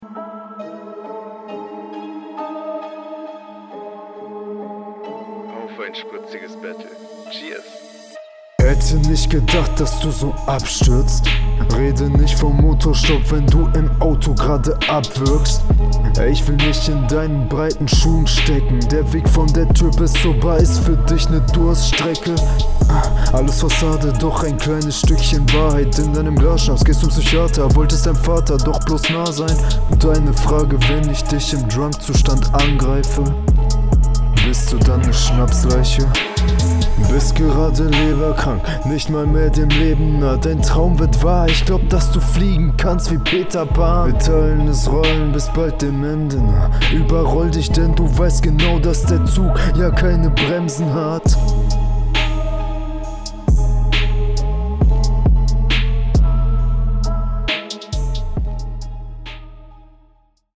Hier kannst du aufgrund der Beatwahl deinen Vorteil ausspielen.